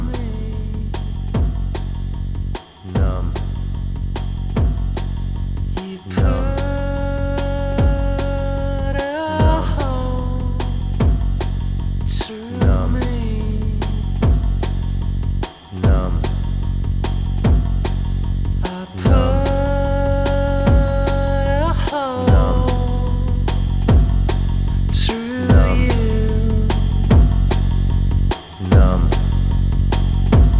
Musique électronique -- Trip hop Pop music